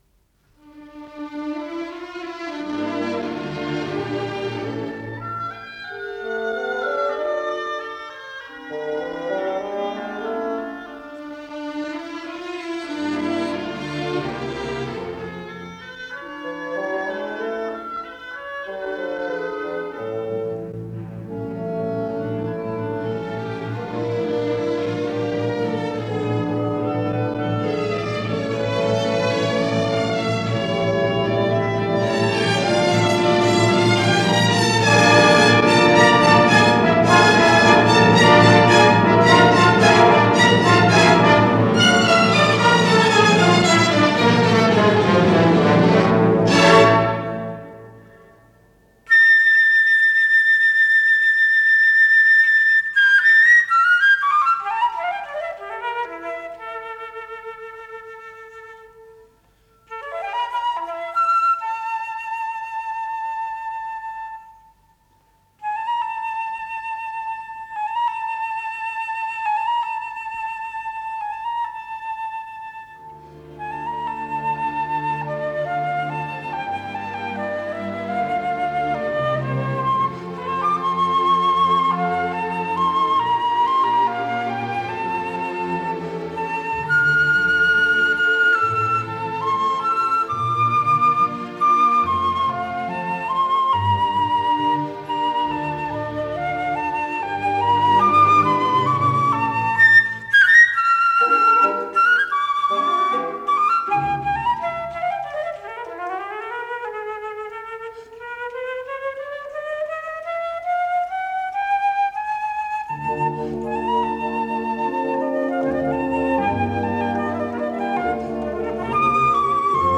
флейта
Ре минор